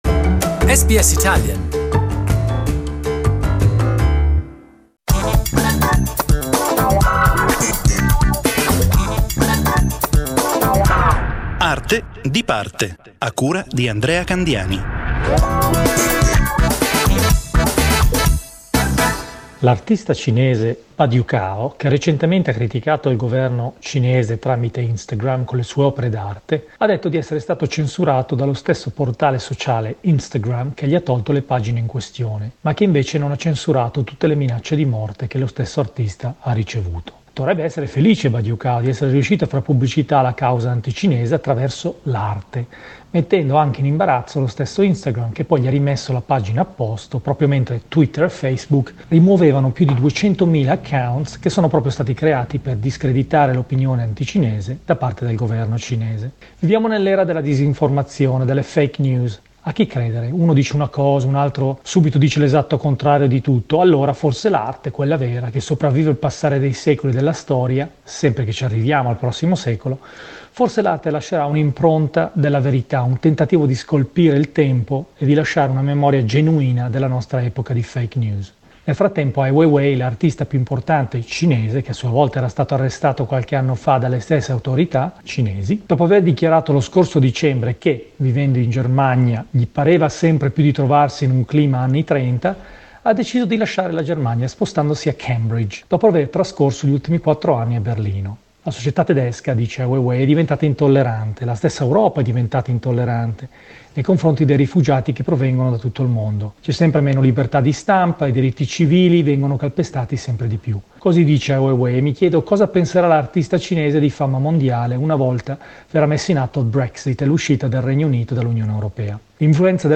SBS Italian